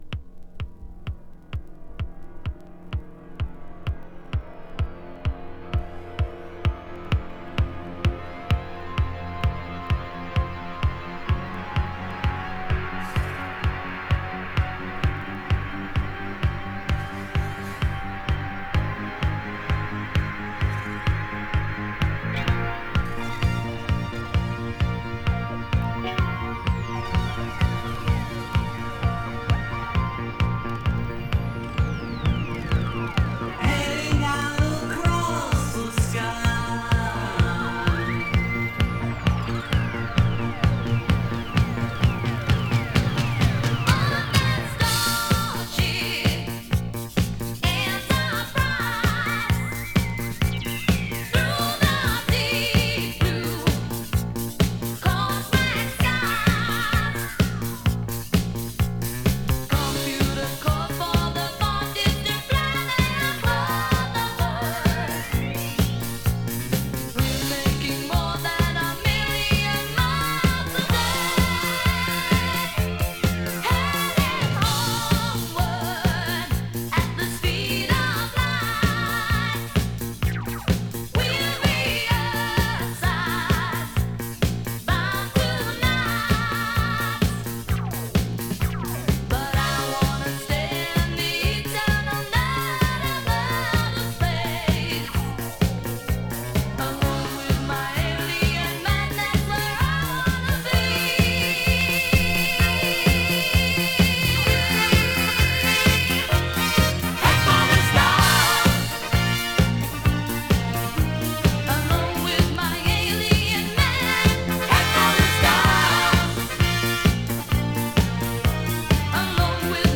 【BOOGIE】
UK産エレクトロ・ブギー！